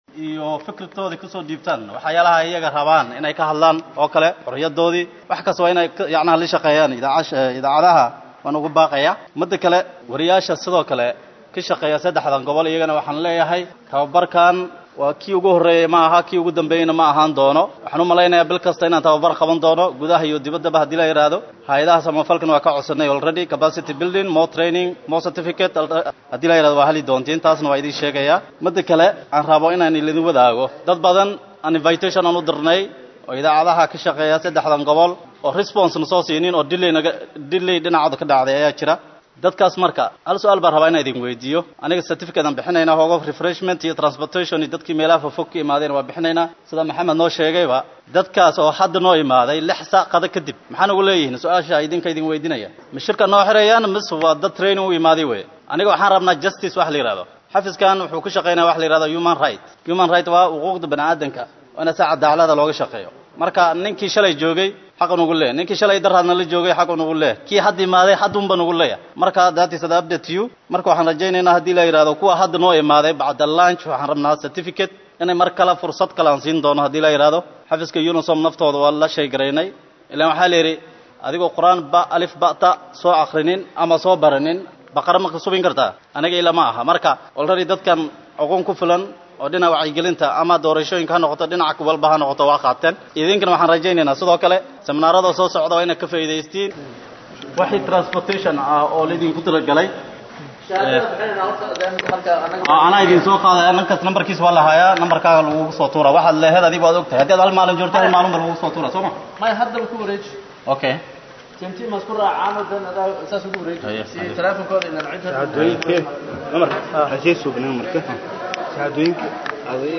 Baydhabo(INO)agaasimaha wasaarada warfaafinta KG ayaa xili uu soo xiraayey aqoon kororsi looqabtay saxafiyiin kakala timid gobolada KG ayaa waxaa uu ku hanjabay in aan shahaadadooda iyo lacagtooda lasiinayni saxafiyiinta qaar waxaana uu arintaa usheegay xili uu lahadlaayey warbaahinta.
Codka-Agaasimaha-.mp3